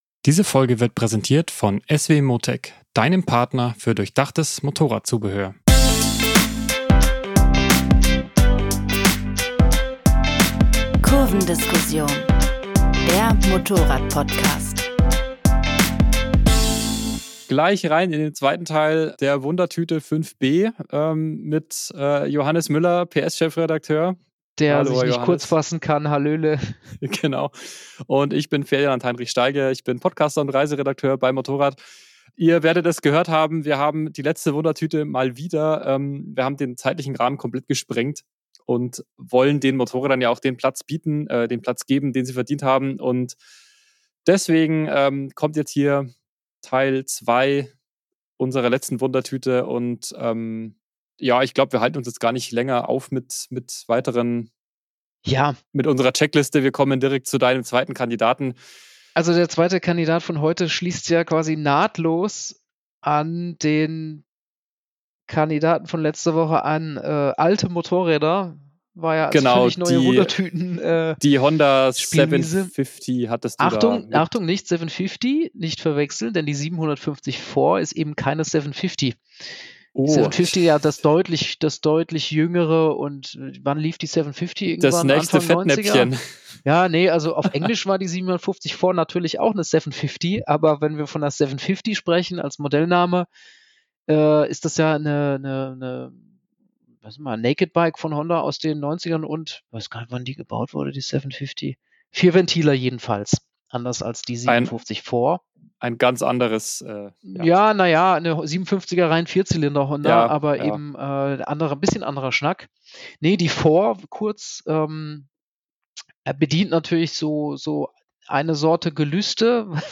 Kurvendiskussion, das sind Benzingespräche am MOTORRAD-Stammtisch, mit Redakteuren und Testern. Es geht um aktuelle Modelle, Trends, Schrauberphilosophie und alles, was uns sonst bewegt.